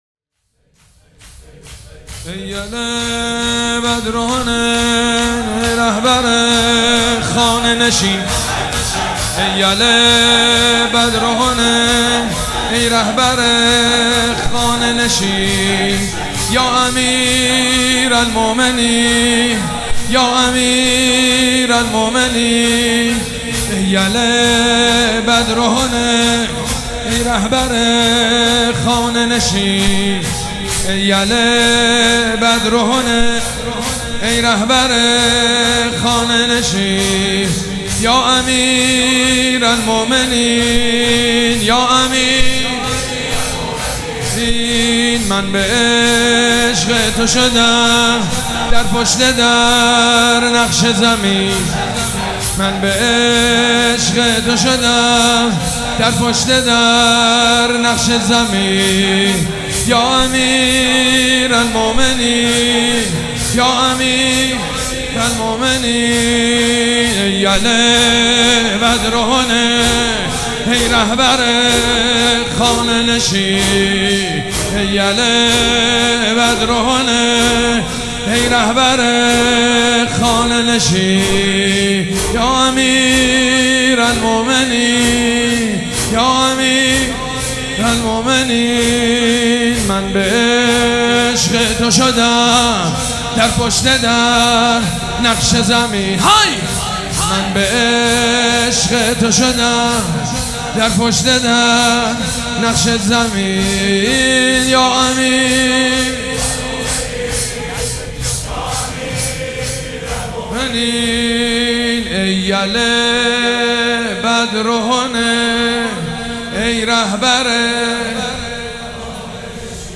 دودمه
مداح
حاج سید مجید بنی فاطمه
مراسم عزاداری شب شهادت حضرت زهرا (س)